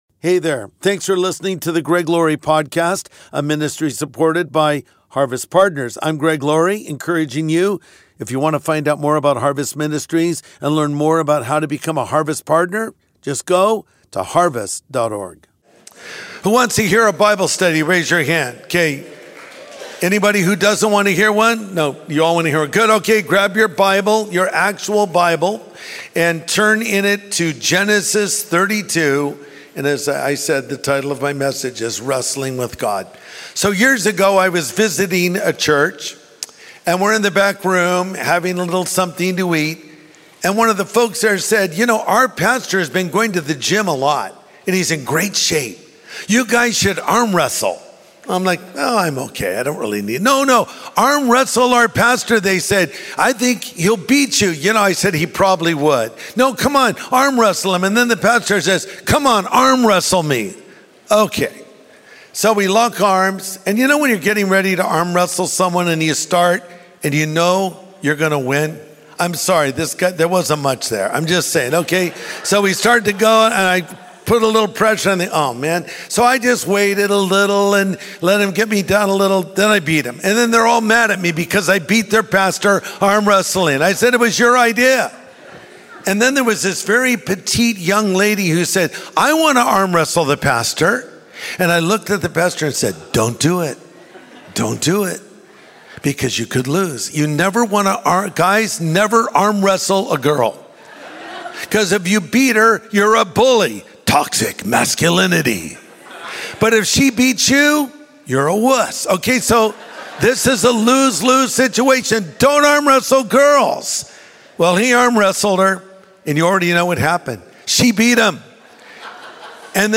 Wrestling with God | Sunday Message